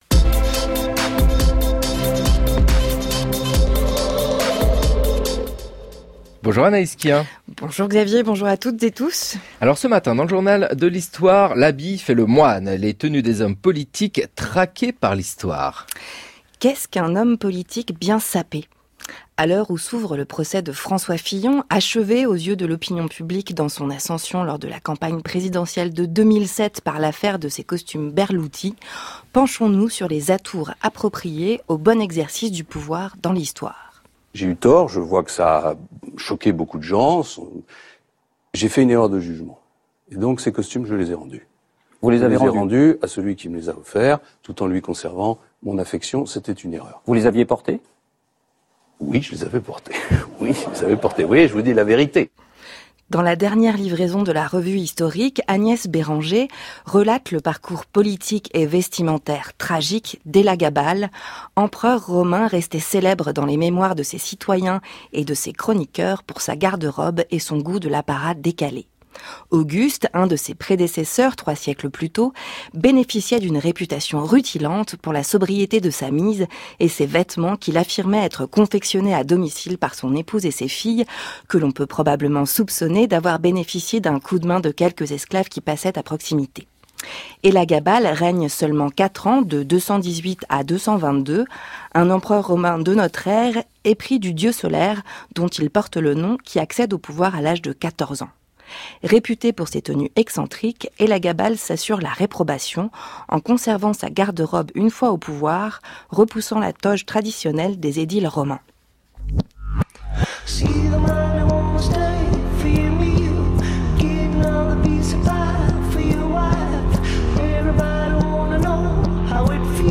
Émission de radio Chronique